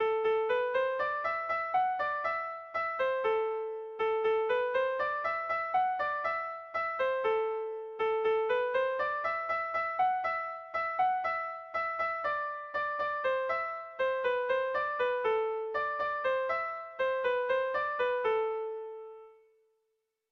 Air de bertsos - Voir fiche   Pour savoir plus sur cette section
Irrizkoa
Lauko txikia (hg) / Bi puntuko txikia (ip)
7 / 6A / 7 / 6A (hg) | 13A / 13A (ip)
AB